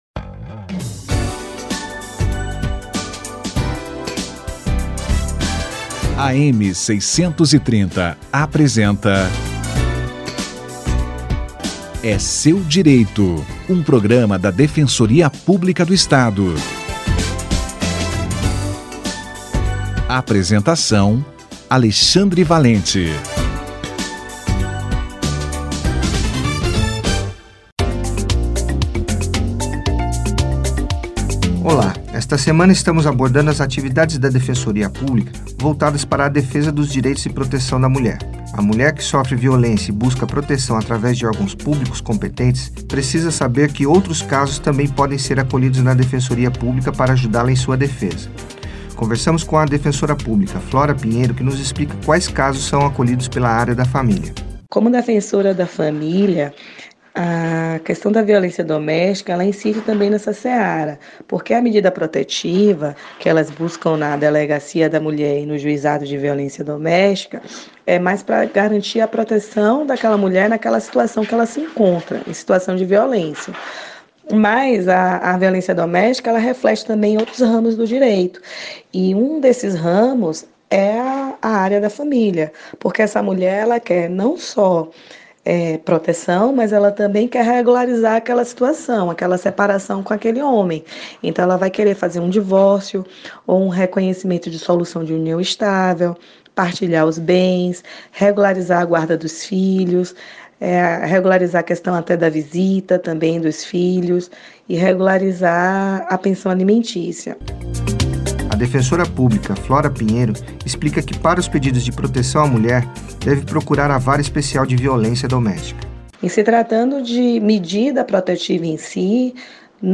Atendimento à mulher vítima de violência - Entrevista